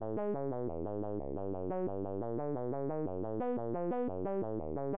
Assignment 8 is to imitate the sound of a classical modular analog synthesizer doing what it does best: subtractive synthesis.
To generate the pitches you could use FM or waveshaping or (as I did), confect a triangle wave which you could simply draw into a wavetable or make using arithmetic operations on a sawtooth wave (that would be harder but more exactly controllable).
my example the center frequiency ranged from MIDI 80 down to MIDI 40 over the length of each note. I didn't apply any enveloping to the amplitude, but just let the filter do all the work.